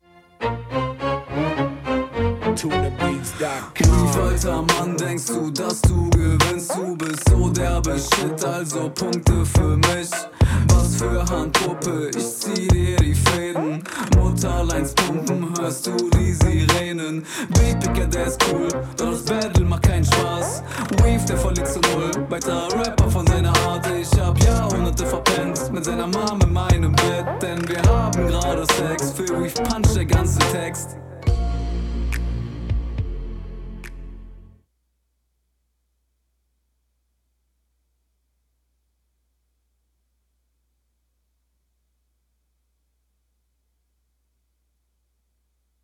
Flowlich mehr getraut und das kommt gut. Generell bessere Audio.